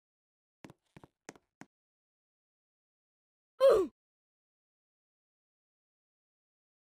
The original oof sound is sound effects free download